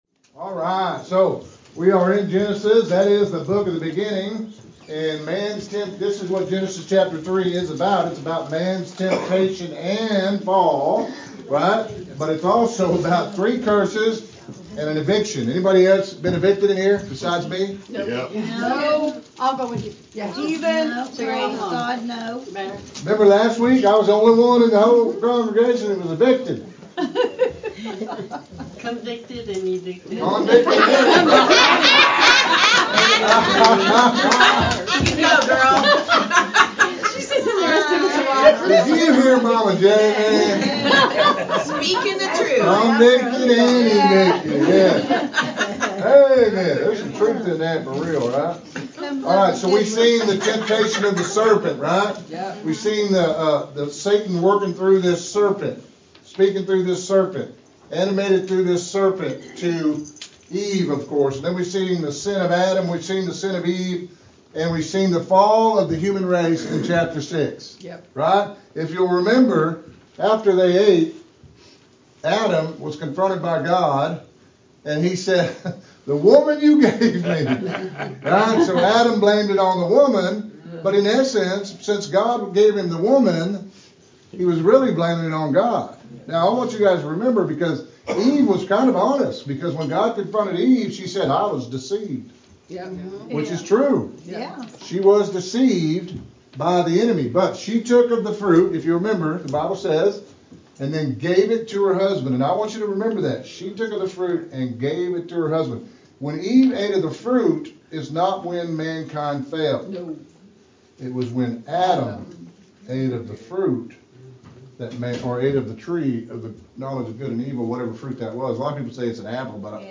Interactive Bible Study